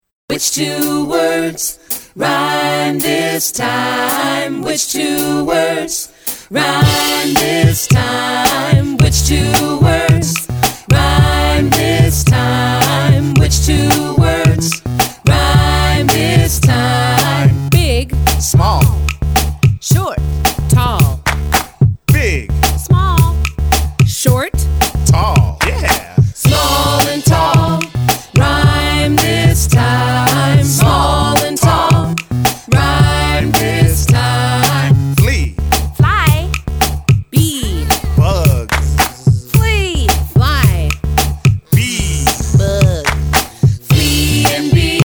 Rap and sing about counting, friendship, shapes and coins